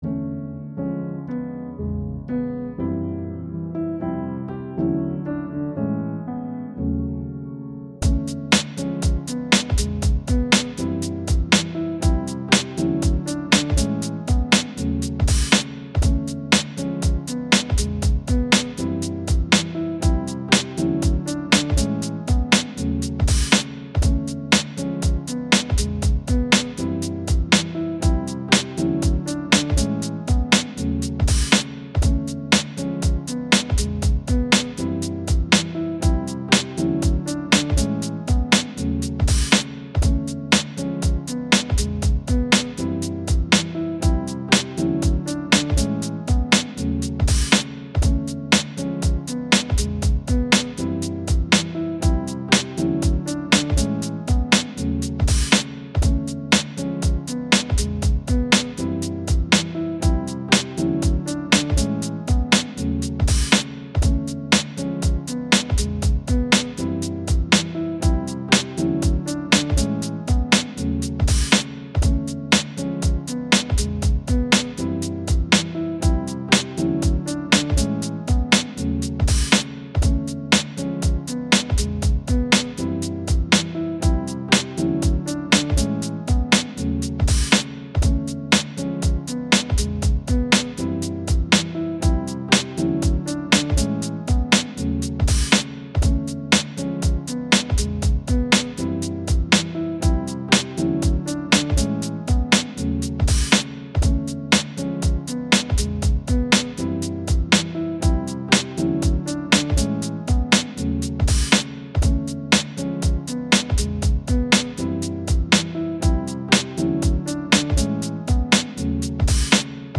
j dilla lofi boom bap instrumental 90s sample sampled hiphop beat chill